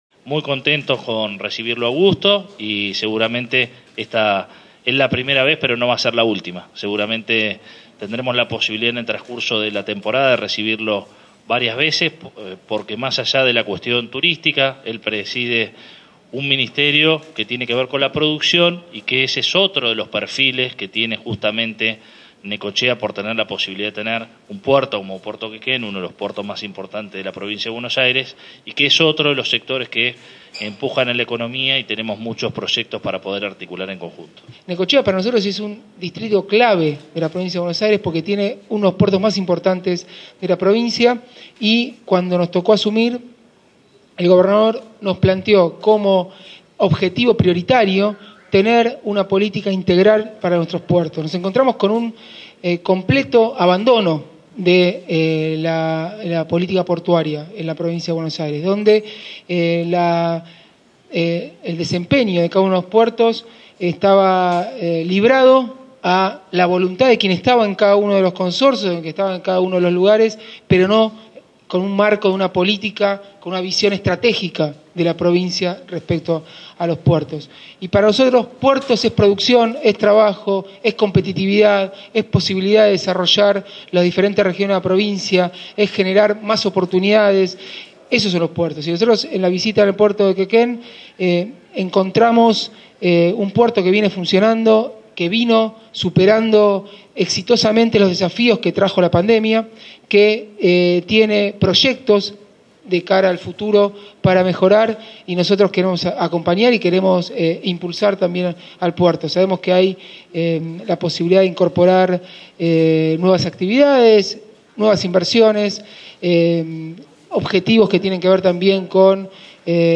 Luego del acto de entrega de subsidios para 134 prestadores del sector turístico y cultural del distrito, el intendente Arturo Rojas y el ministro de Producción, Ciencia e innovación Tecnológica de la Provincia, Augusto Costa, brindaron una conferencia de prensa en la Secretaría de Turismo, ocasión en la que reiteraron lo fundamental que es para los municipios este tipo de ayuda en épocas de pandemia y ante la inminente llegada de la temporada, pero también brindaron aspectos de la visita que ambos realizaron al mediodía al Consorcio de Gestión de Puerto Quequén.